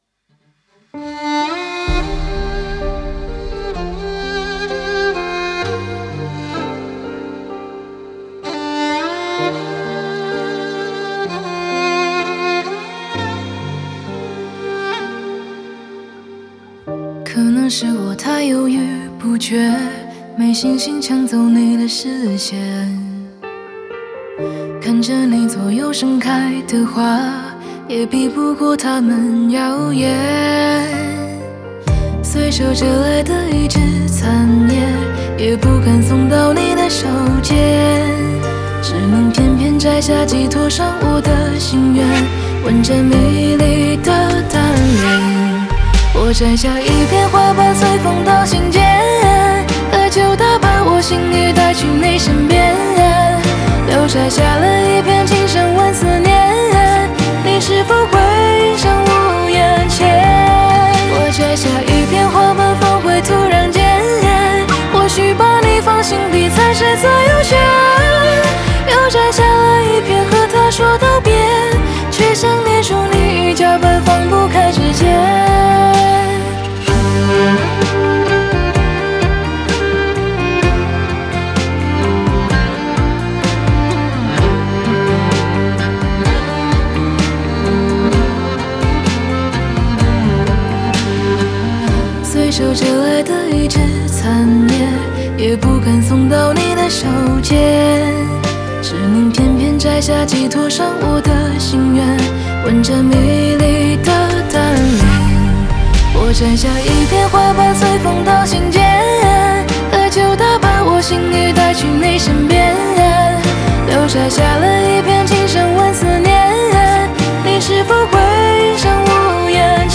吉他
和声
马头琴